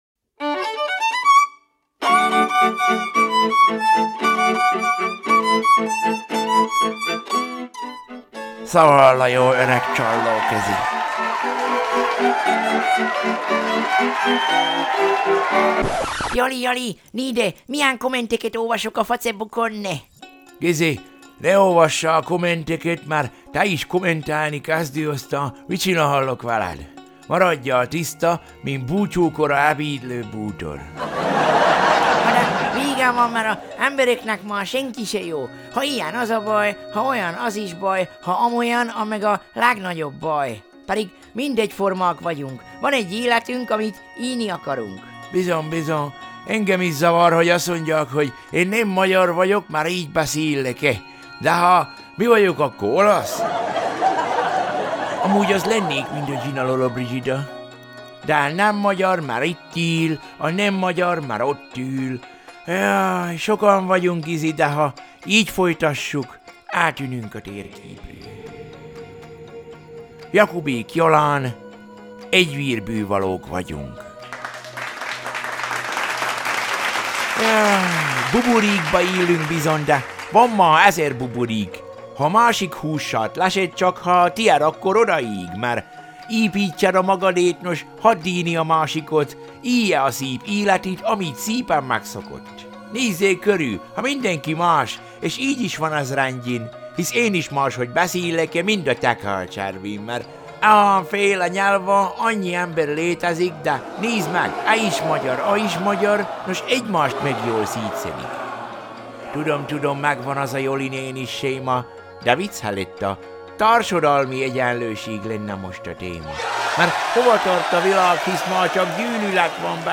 Szaval a jó öreg csallóközi